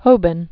(hōbən), James 1762?-1831.